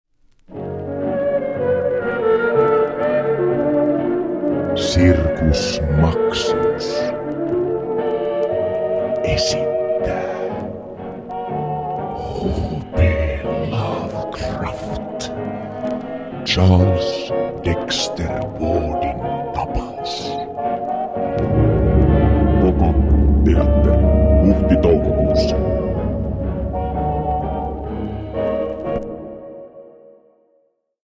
RADIOMAINOS (484kb Mp3)